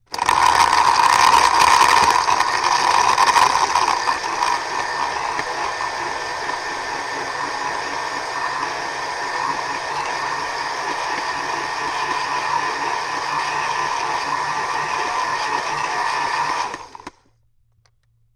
Звук блендера смешивает мороженое с орехами шоколадом и сиропом